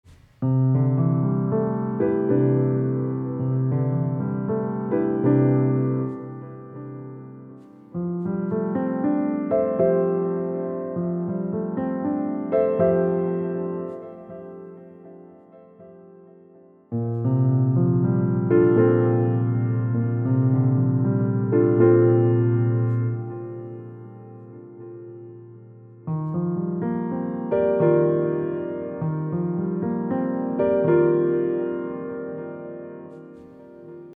All I’m doing is layering the incomplete Lydian scale runs with the degrees of the 3rd degree of the same scale (iii7 in this case) played in triad and rootless jazz voicing (in this example it’s 7-3-5).
Plus — the whole figure creates a very obscure sound that could be easily used as an accompaniment device.
lydian-clouds-i-e28094-ii.mp3